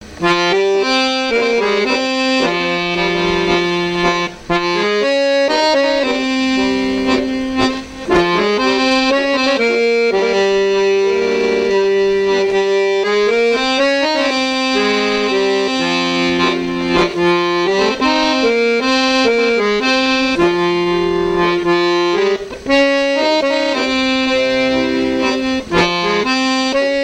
danse : tango
Pièce musicale inédite